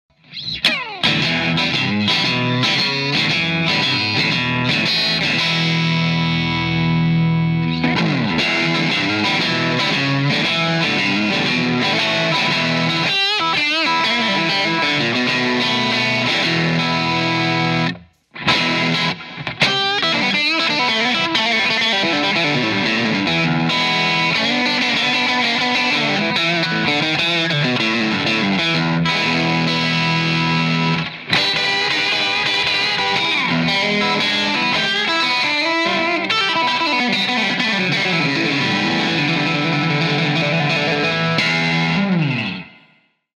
VOX Soundcheck: SRV-style tones with the VOX Valvetronix VT20X using the Deluxe CL amp model